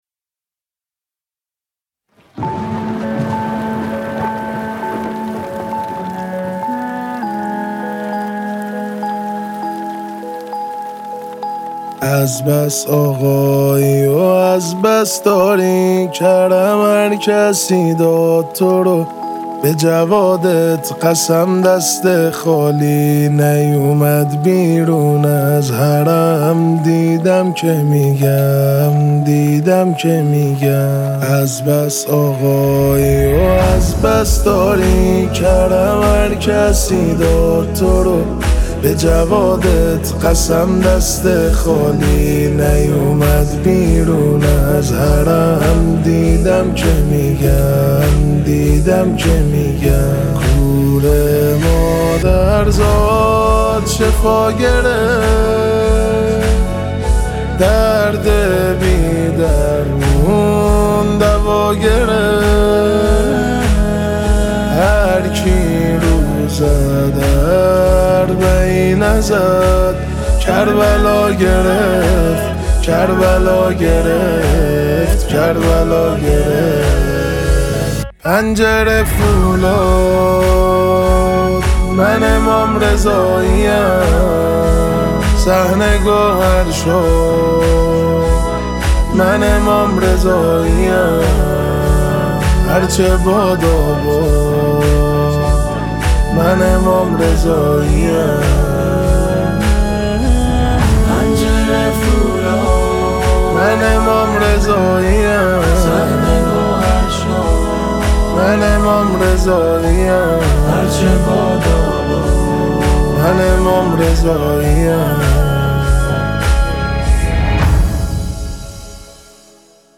نماهنگ